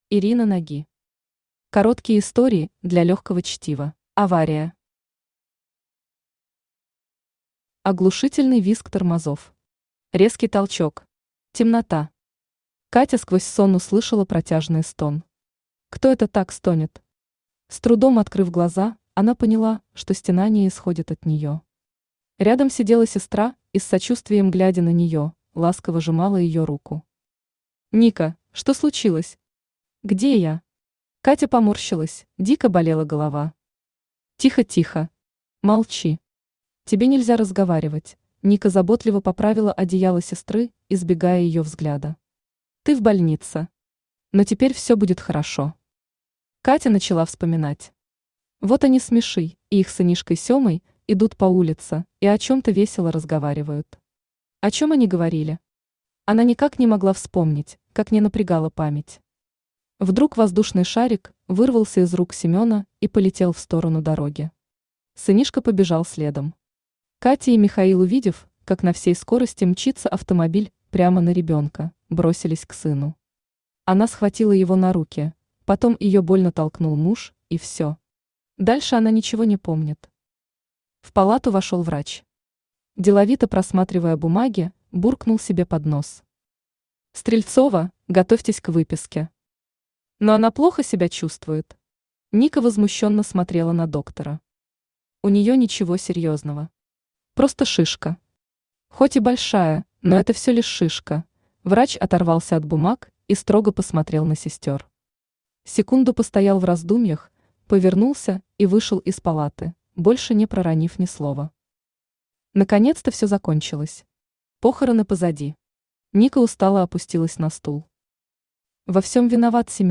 Aудиокнига Короткие истории для легкого чтива Автор Ирина Наги Читает аудиокнигу Авточтец ЛитРес.